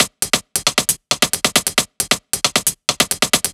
Flicky Break 135.wav